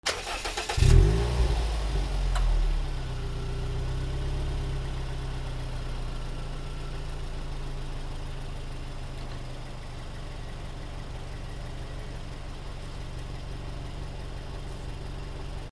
200 車 エンジンをかける
/ E｜乗り物 / E-10 ｜自動車